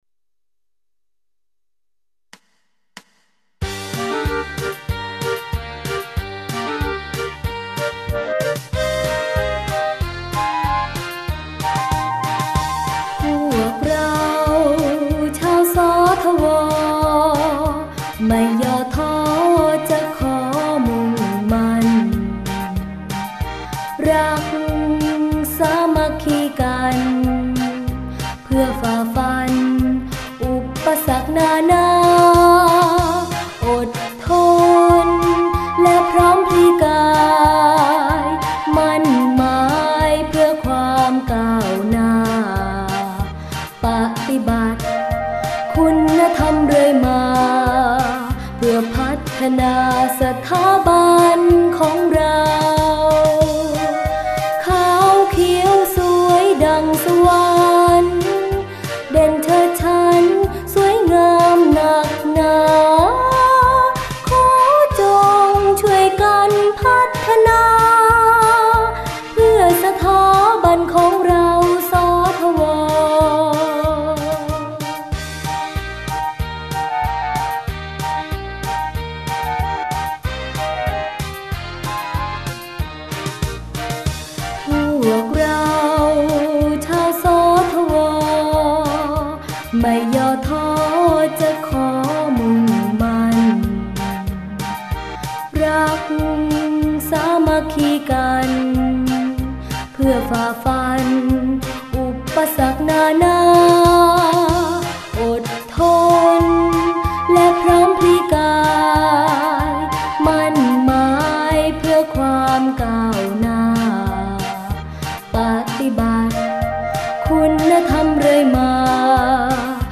เพลงมาร์ชโรงเรียน